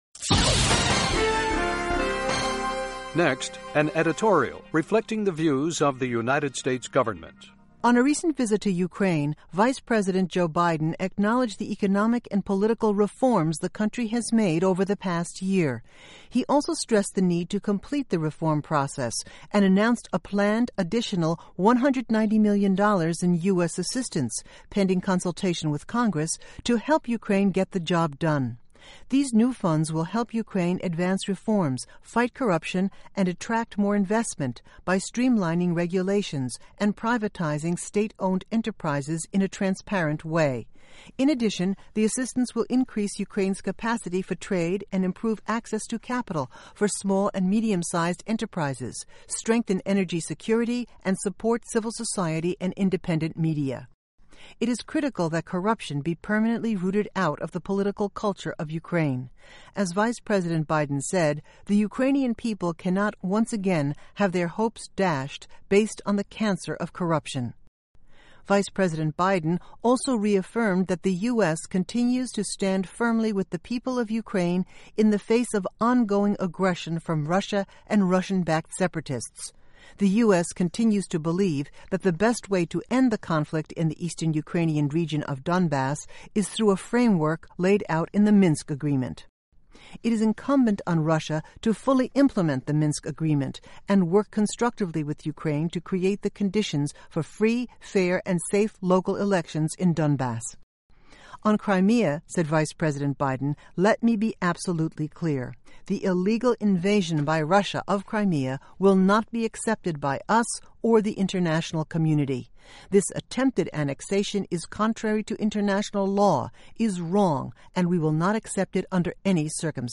Radio Editorials